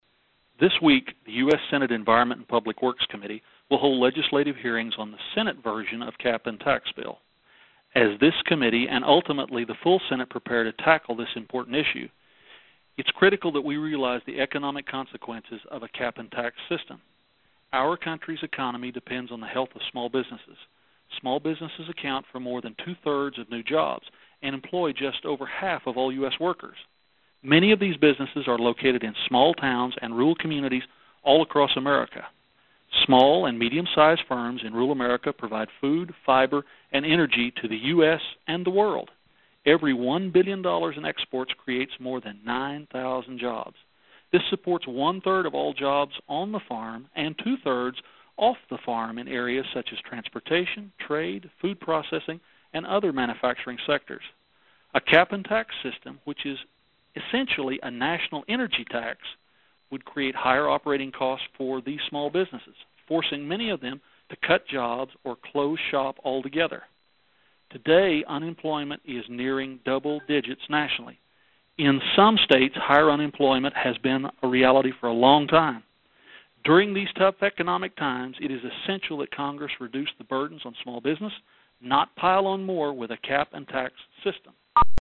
The Ag Minute is Ranking Member Lucas' weekly radio address that is released each Tuesday from the House Agriculture Committee Republicans.